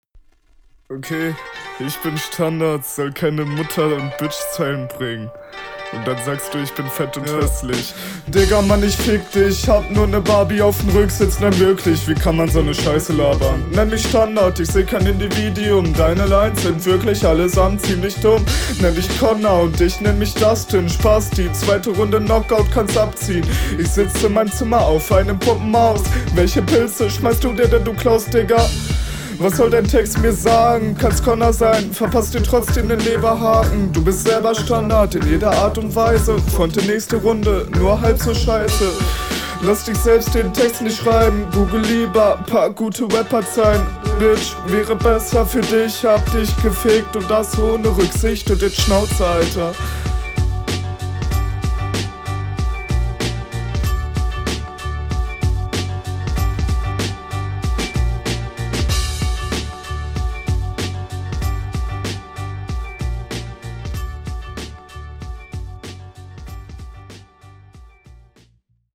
Flow hat gute Ansätze, jedoch gibt es 1-2 ausfälle.
Kommt auch sehr Solide auf den Beat und die Mische is gut, Flowlich gut aber …